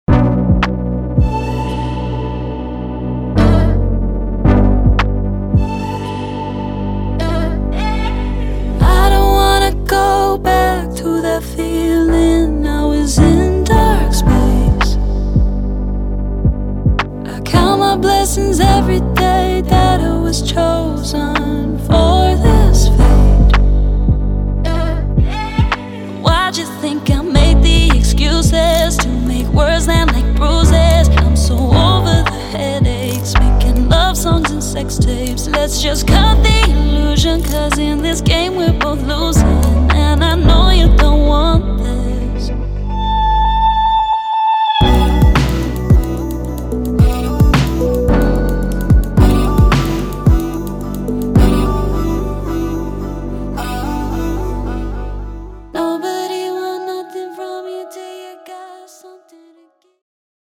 Veiled in melancholy